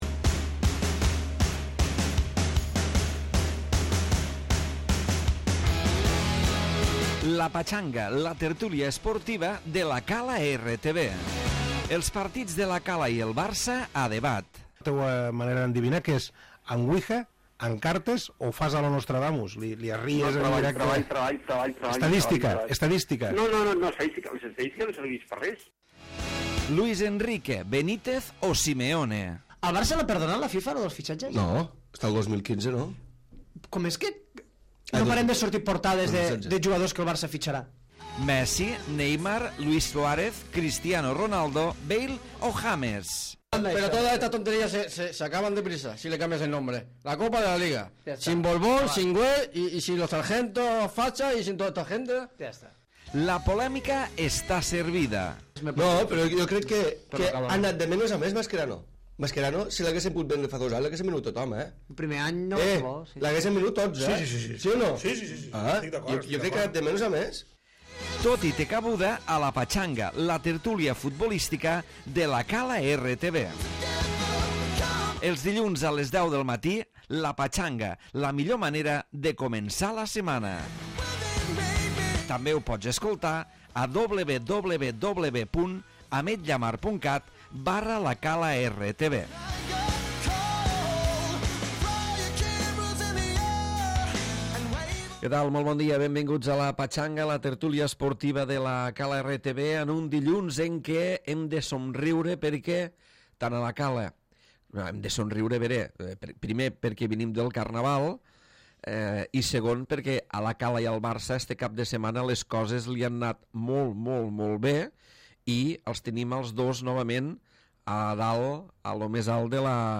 tertúlia d'actualitat futbolistica